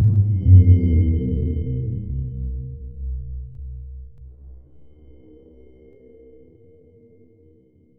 Low End 08.wav